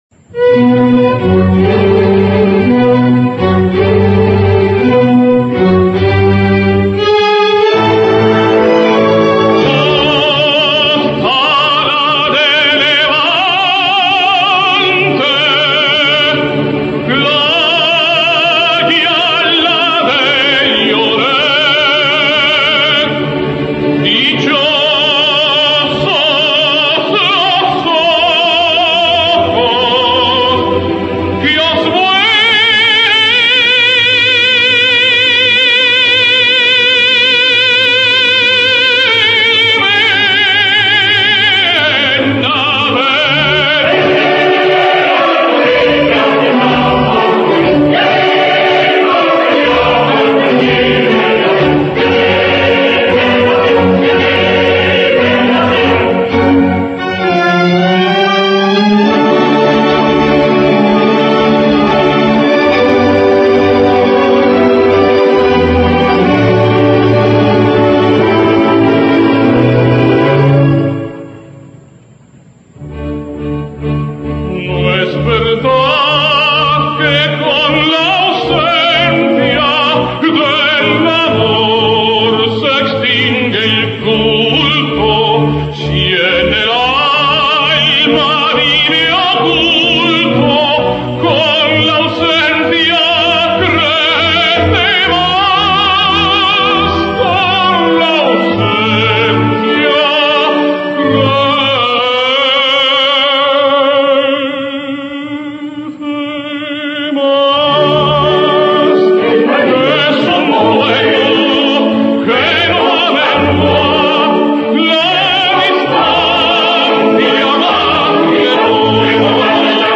Alfredo Kraus sings Marina: